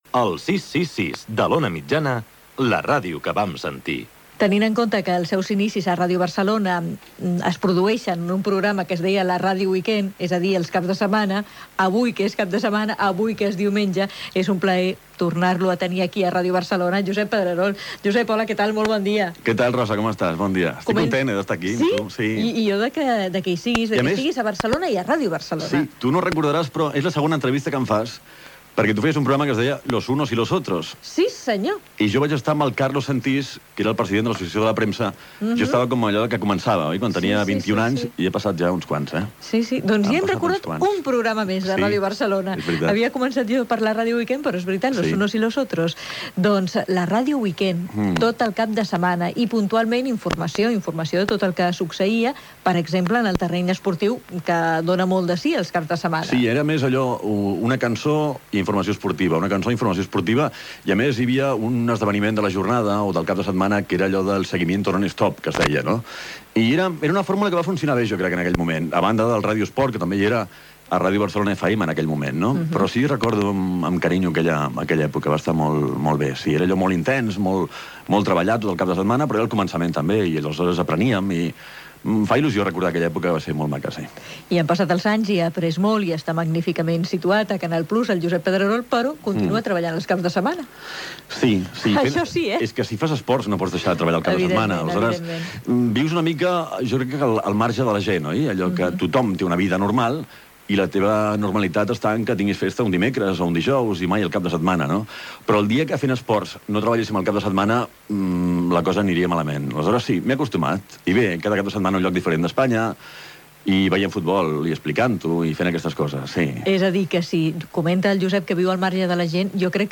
Identificació de l'emissora. Entrevista al periodista Josep Pedrerol.
Divulgació